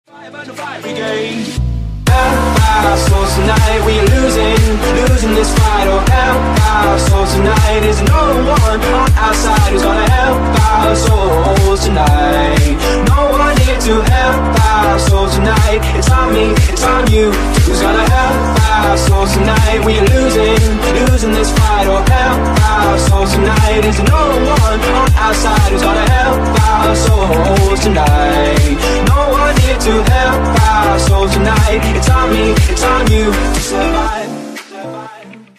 мужской вокал
громкие
deep house
Indie Dance